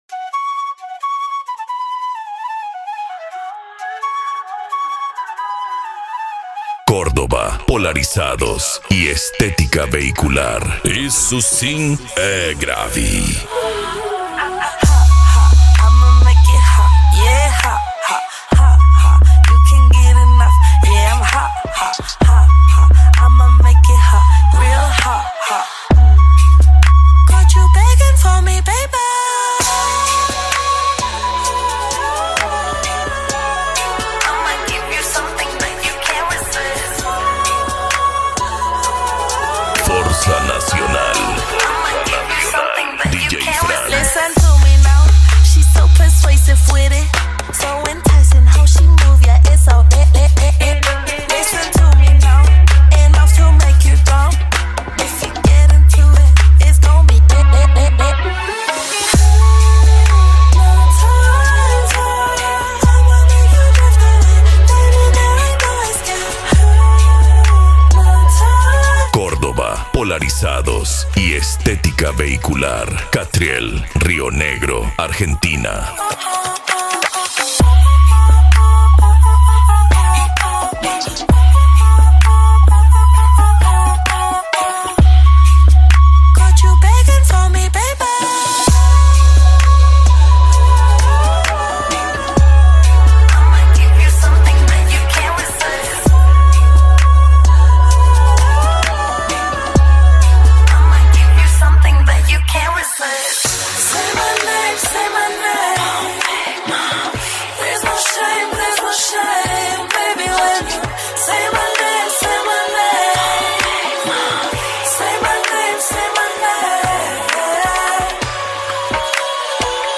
Bass
Electro House
Eletronica
Musica Electronica